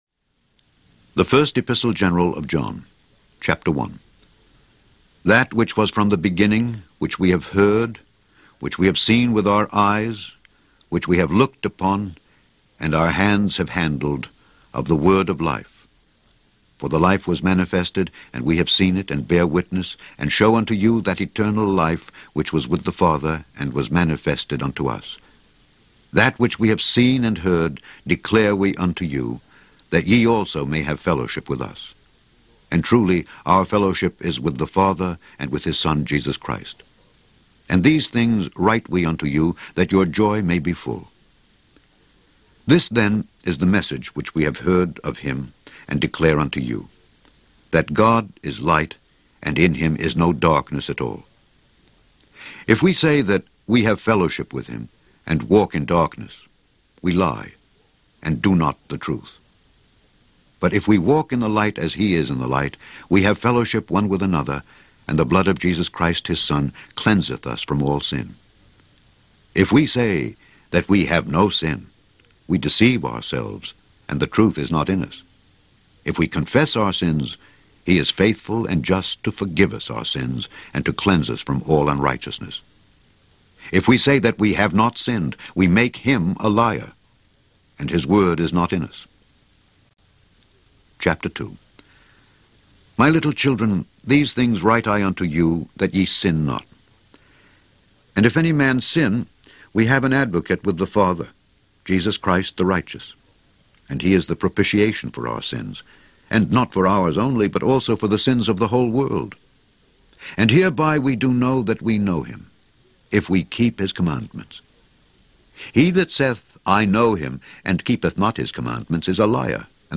Scourby Audio Bible